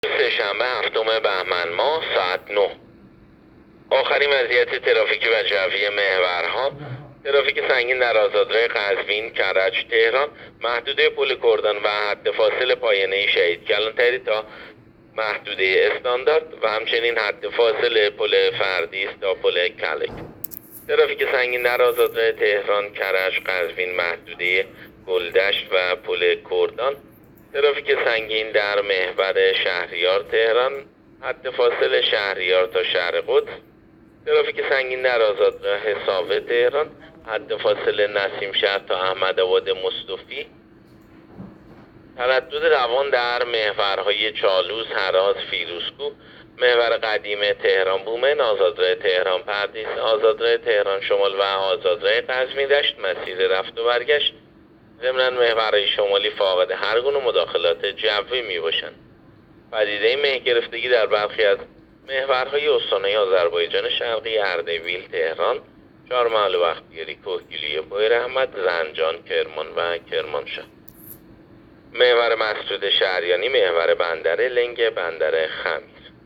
گزارش رادیو اینترنتی از آخرین وضعیت ترافیکی جاده‌ها ساعت ۹ هفتم بهمن؛